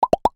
Multi Pop Four Top Sound Buttons